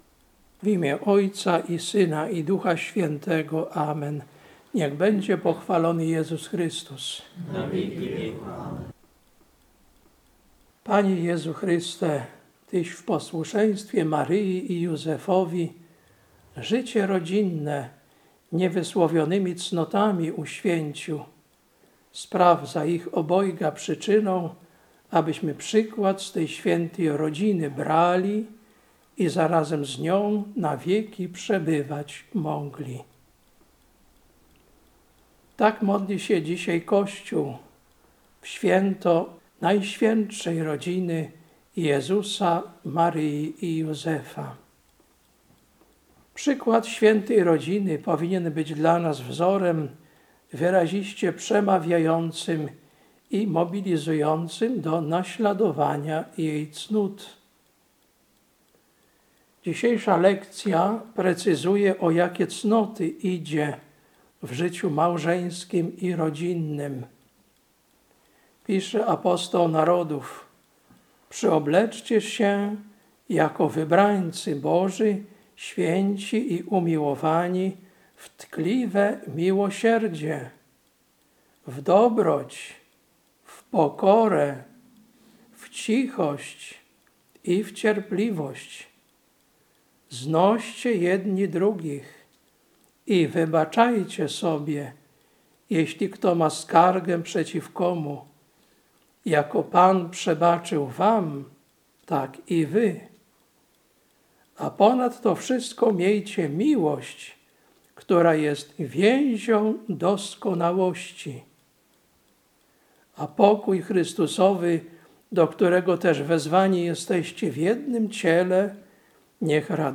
Kazanie na uroczystość Świętej Rodziny Jezusa, Maryi i Józefa, 12.01.2025 Lekcja: Kol 3, 12-17 Ewangelia: Łk 2, 42-52